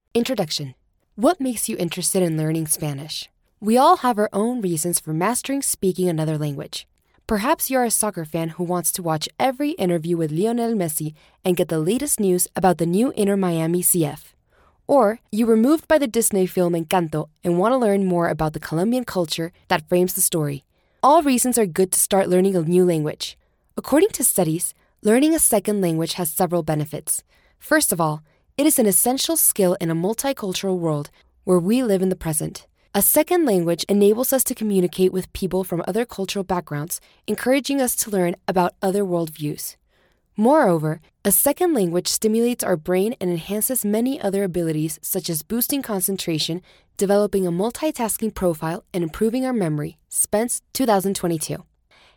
E-Learning
Wenn Sie eine jugendliche Stimme sowohl für Englisch als auch für Spanisch suchen, werden Sie es nicht bereuen, meine für Ihr Projekt ausgewählt zu haben!
Home Studio, Rode NT1 Kondensatormikrofon der 5. Generation